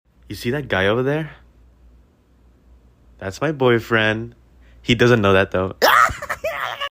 hahaaha sound effects free download
You Just Search Sound Effects And Download. tiktok hahaha sound effect Download Sound Effect Home